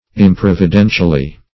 Improvidentially \Im*prov`i*den"tial*ly\, adv.
improvidentially.mp3